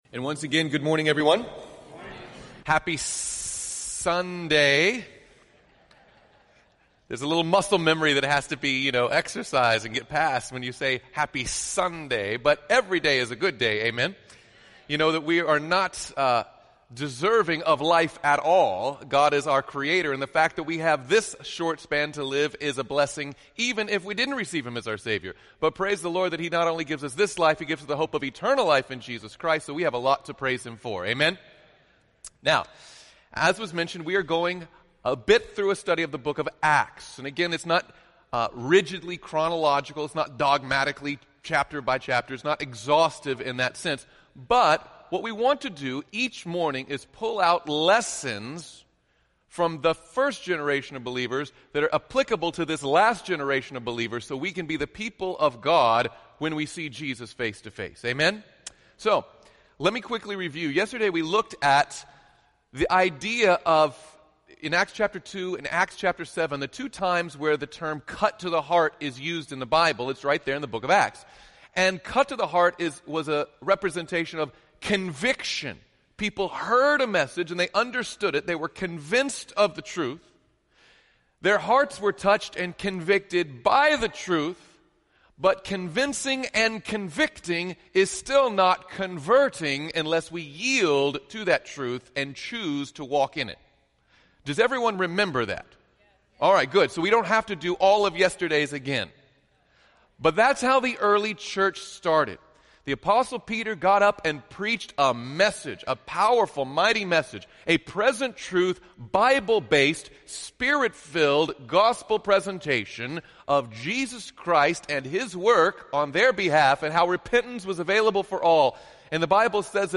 This sermon entitled Discipline